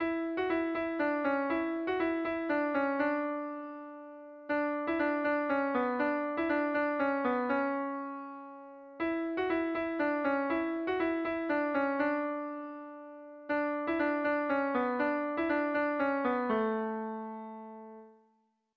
Haurrentzakoa
Zortziko txikia (hg) / Lau puntuko txikia (ip)
AB1AB2